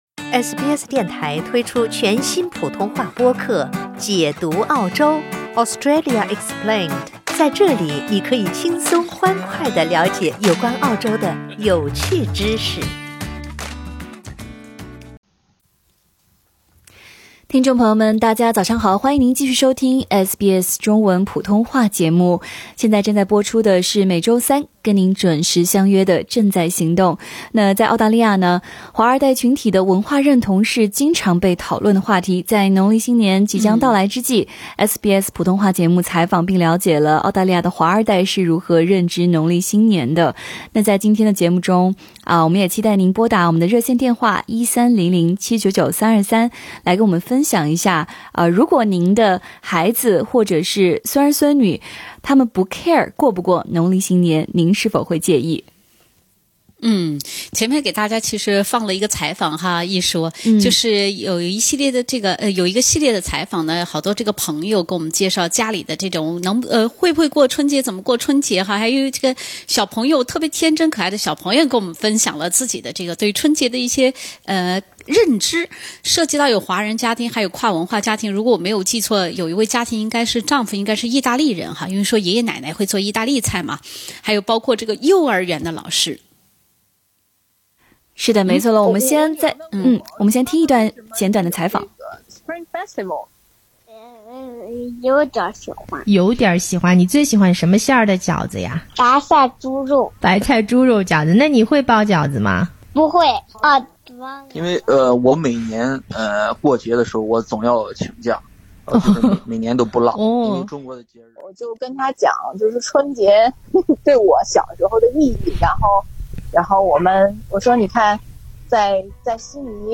在农历新年即将到来之际，SBS普通话节目采访并了解了澳大利亚的华二代是如何认知农历新年的。 在今天8点20分的正在行动话题：您的孩子或孙儿孙女如果不care过不过农历新年，你介意吗？